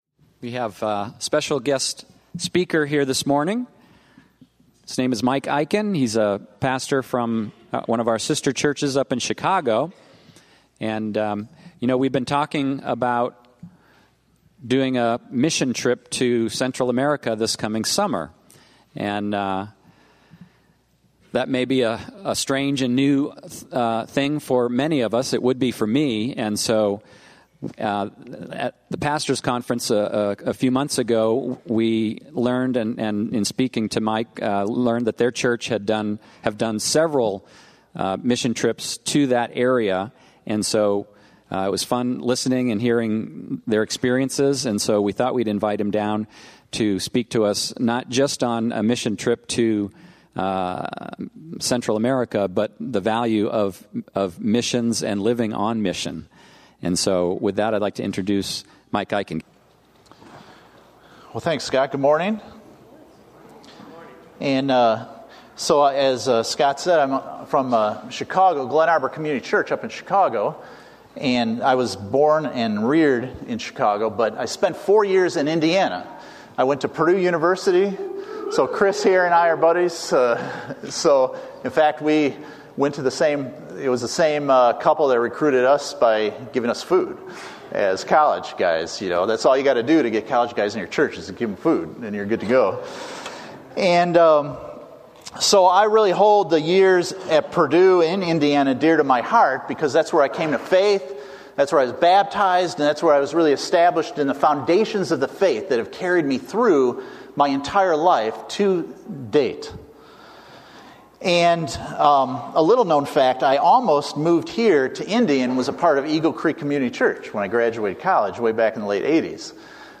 Why Go On A Missions Trip? (message given in Indy)